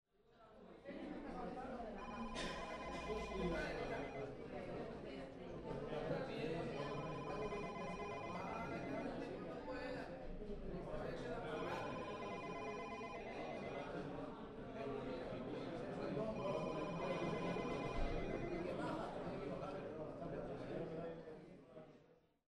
Ambiente de un locutorio